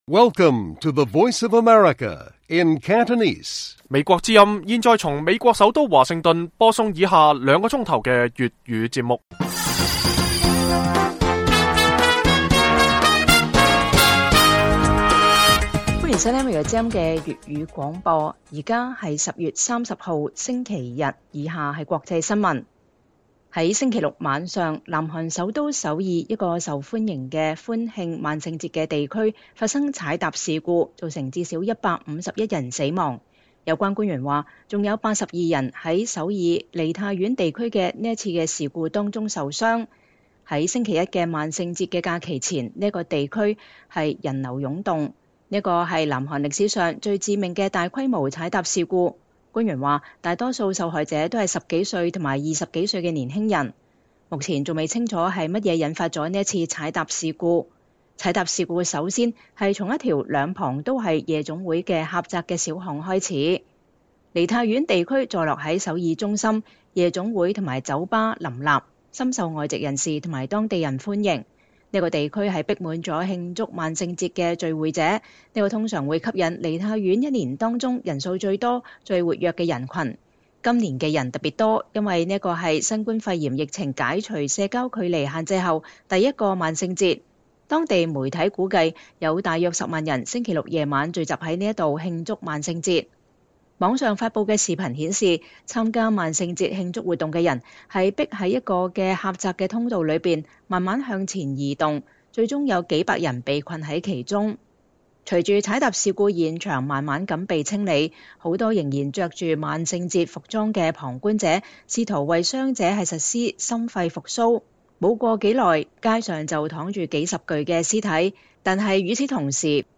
粵語新聞 晚上9-10點：首爾萬聖節發生踩踏事故 造成至少151人死亡